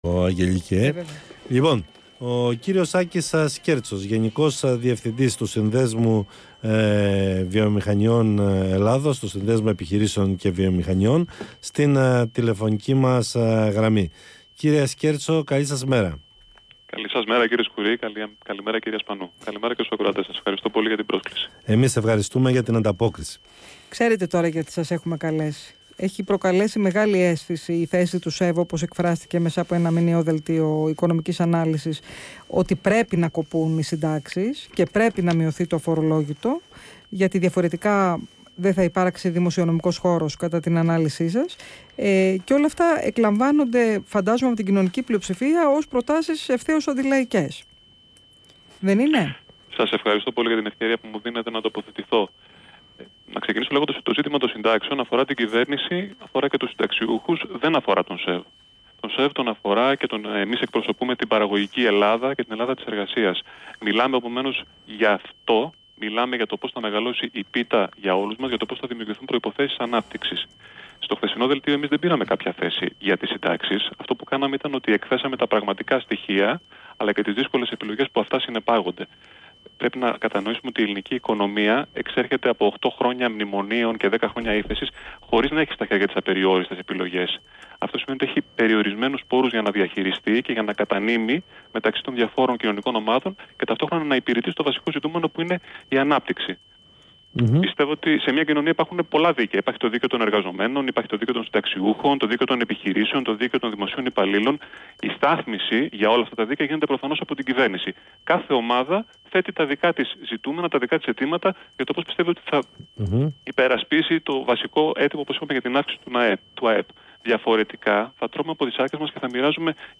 Συνέντευξη του Γενικού Διευθυντή του ΣΕΒ, κ. Άκη Σκέρτσου στο ΡΑΔΙΟΦΩΝΟ 247, 16/10/2018